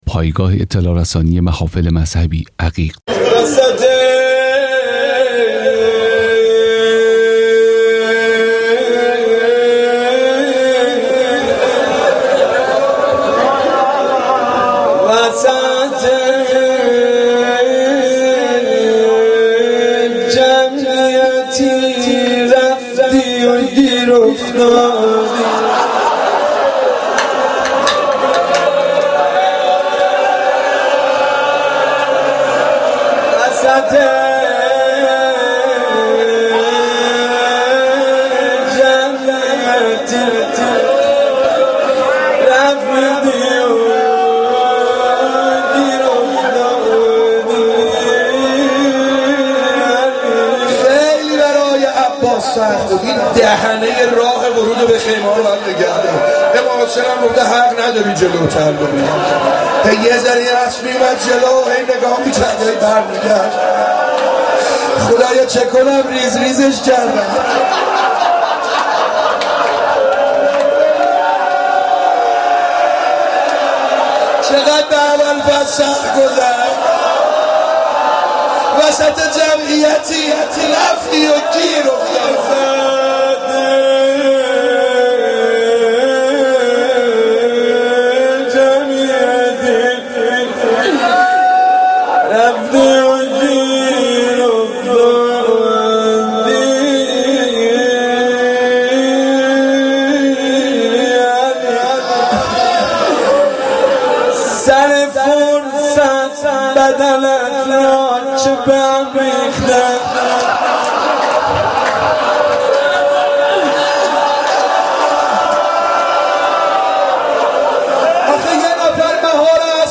صوت / روضه علی اکبر (ع) در شب هجدهم صفر